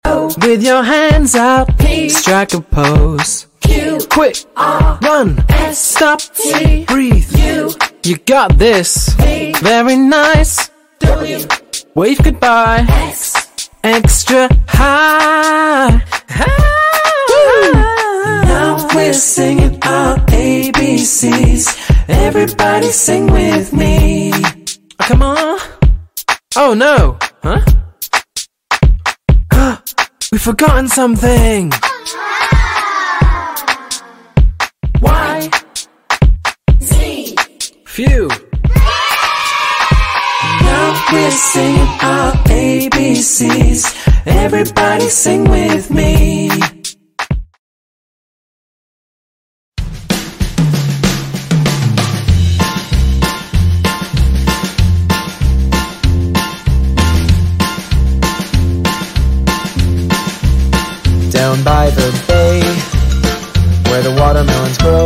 Music for Kids ｜ Kindergarten Songs for Children